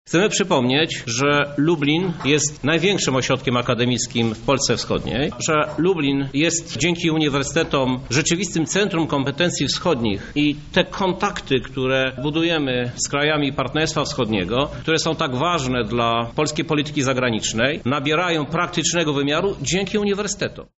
Oczekiwać będziemy takiego samego programu dla lubelskiego ośrodka akademickiego – mói Krzysztof Żuk, prezydent Lublina